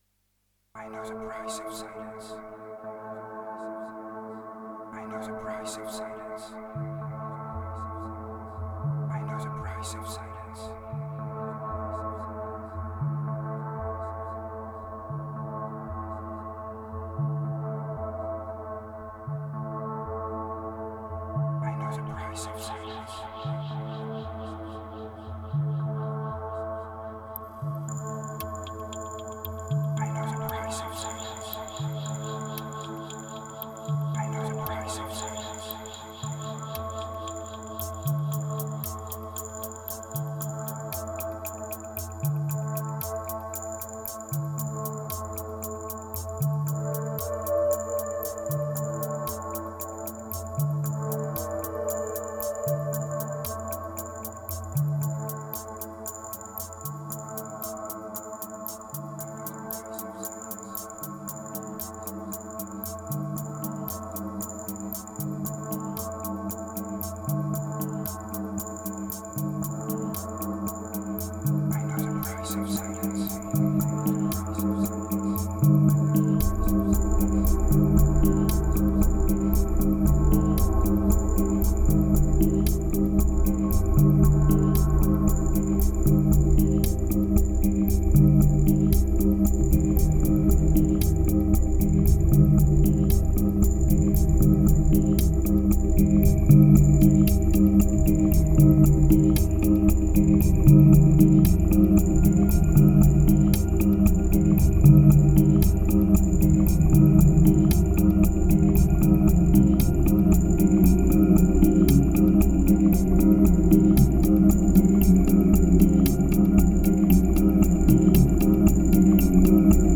2143📈 - 15%🤔 - 115BPM🔊 - 2014-05-18📅 - -109🌟